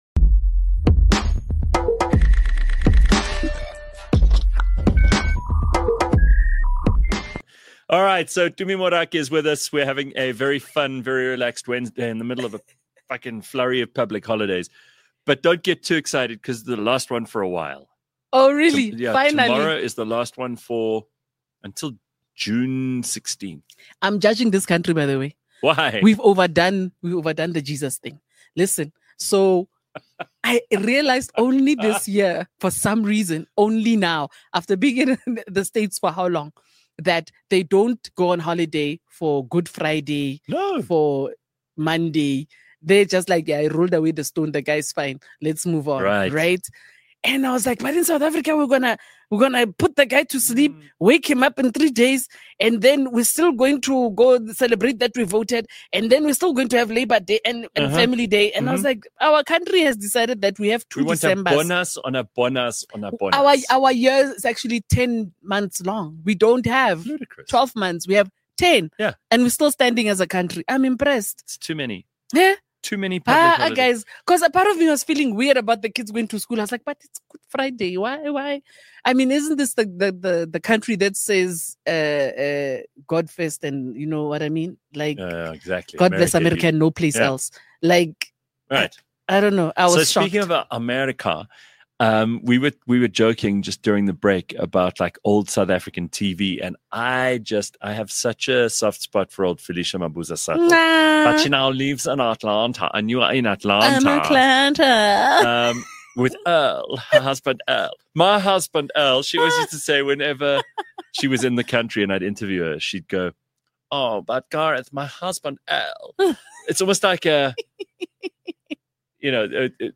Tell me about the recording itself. A live podcast show, that’s like a morning radio show, just much better. Live from 6h00-8h00 Monday, Wednesday and Friday. Clever, funny, outrageous and sometimes very silly.